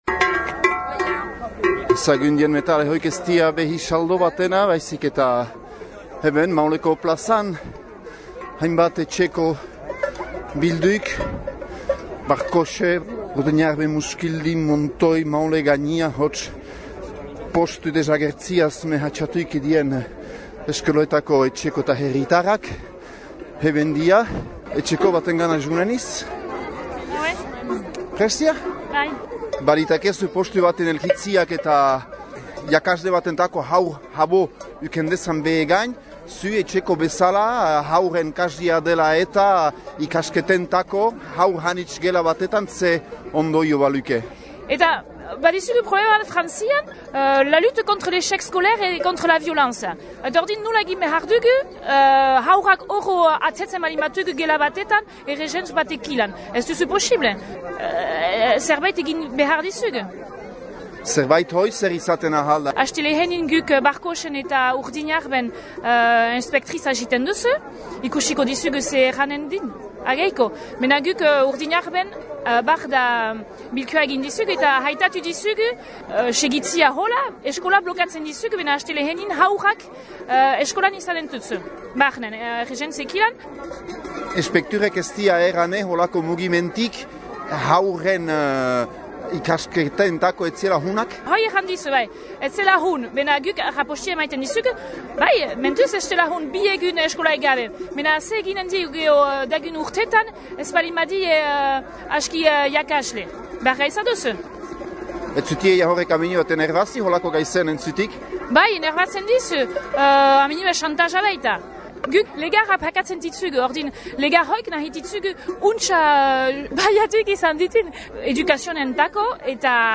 Entzün manifestaldian bildürik izan diren lekükotarzünak :